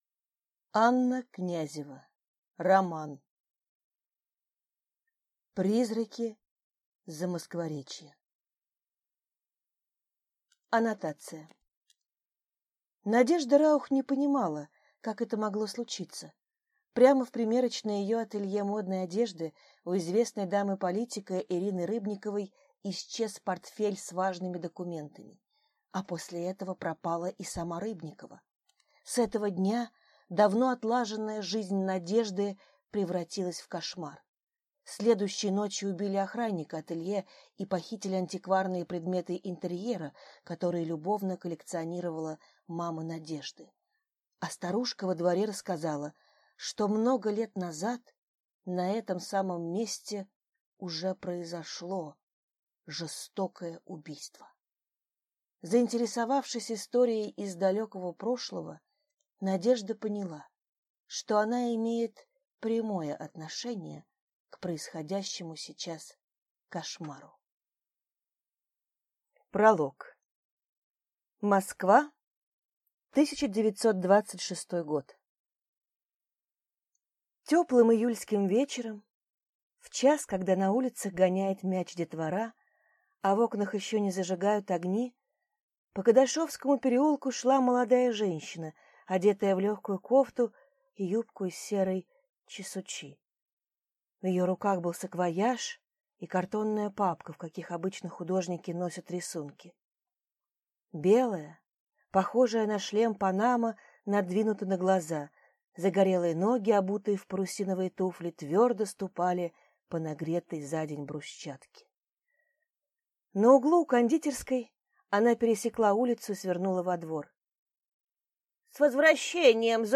Аудиокнига Призраки Замоскворечья | Библиотека аудиокниг